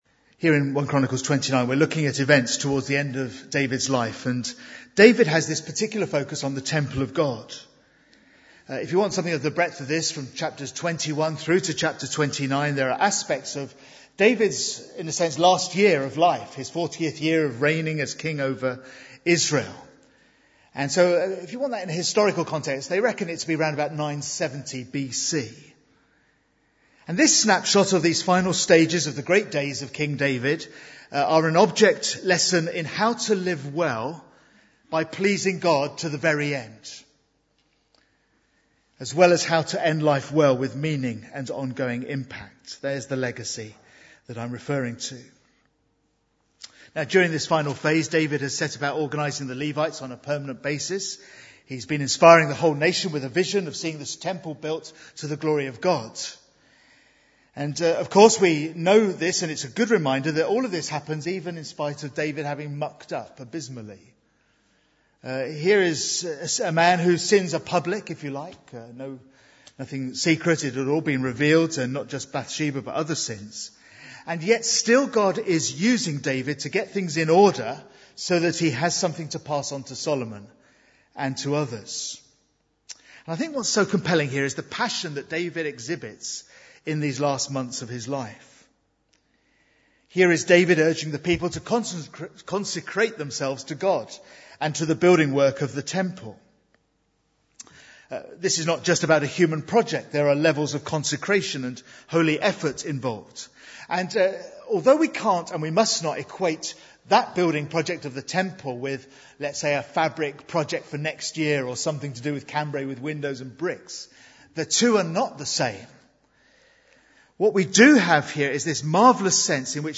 Bible Text: 1 Chronicles 29:1-20 | Preacher